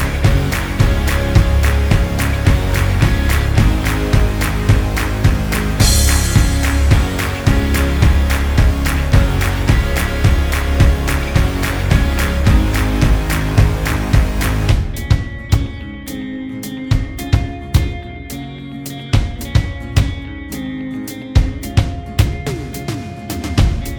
Minus Lead Guitar Pop (2010s) 3:08 Buy £1.50